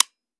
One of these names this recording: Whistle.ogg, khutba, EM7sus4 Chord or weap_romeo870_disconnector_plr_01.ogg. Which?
weap_romeo870_disconnector_plr_01.ogg